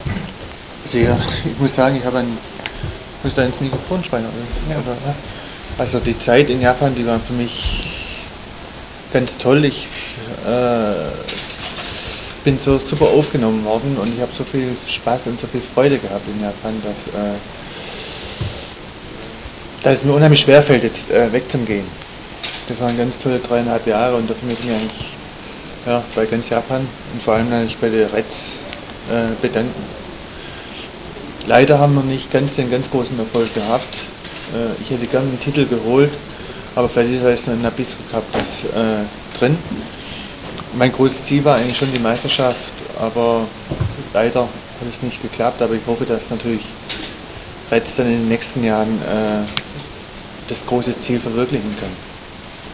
ブッフバルト選手退団会見！
マークはギドの肉声が聞けます！